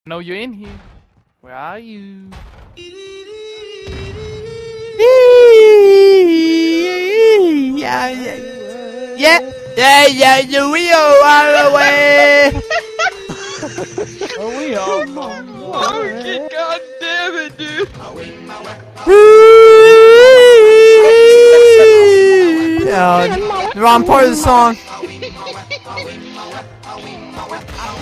Weee Ahhhhh Uhhhh Ahhhh Sound Button | Sound Effect Pro
Instant meme sound effect perfect for videos, streams, and sharing with friends.